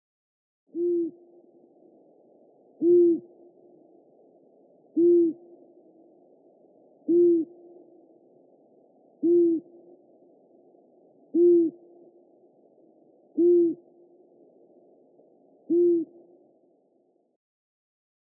Der Ruf der Waldohreule ist eher ruhig und unauffällig. Typisch ist ein tiefes, gleichmäßiges „huu“, das in regelmäßigen Abständen wiederholt wird. Gerade weil der Ruf der Waldohreule so leise ist, wird er oft überhört.
Der Ruf der Waldohreule
Der Ruf der Waldohreule ist leise, regelmäßig und vor allem nachts zu hören.
Ruf-der-Waldohreule-Voegel-in-Europa.mp3